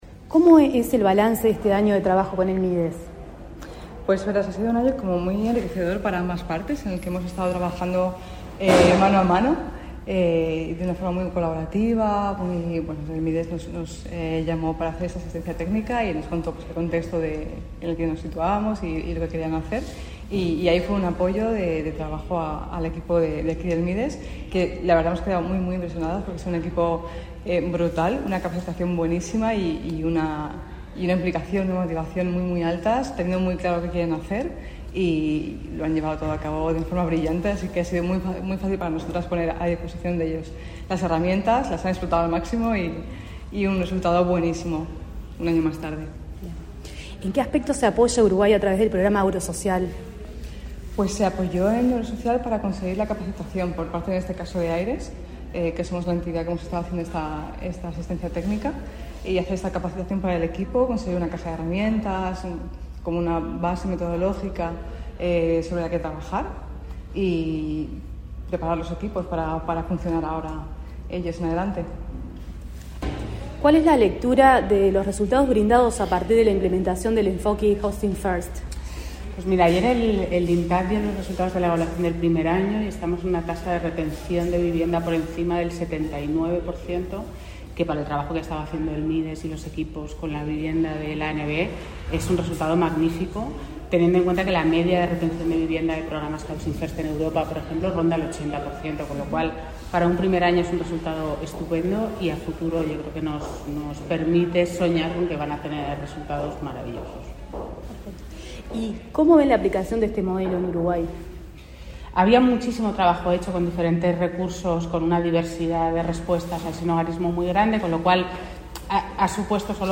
Entrevista a expertas españolas sobre modelo de atención del Mides a personas en situación del calle